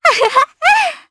Nia-Vox_Happy2_jp.wav